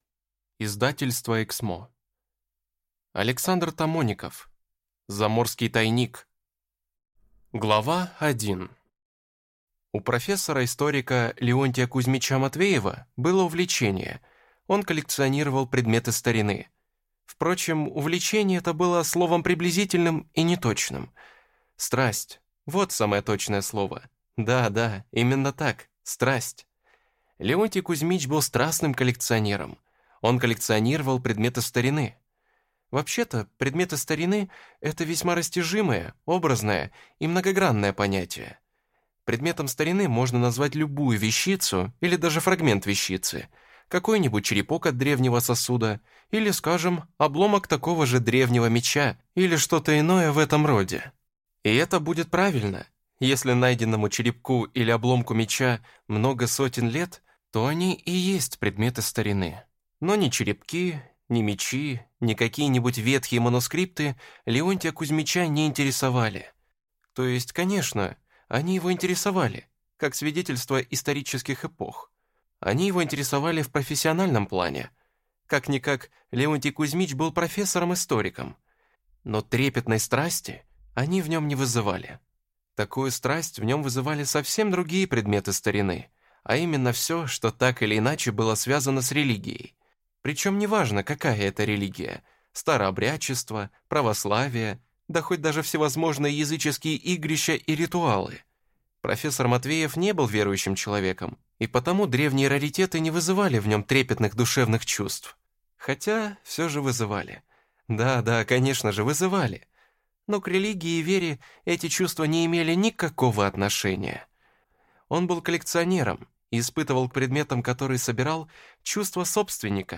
Аудиокнига «Заморский тайник».